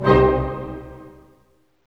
Index of /90_sSampleCDs/Roland LCDP08 Symphony Orchestra/HIT_Dynamic Orch/HIT_Orch Hit Min
HIT ORCHM03R.wav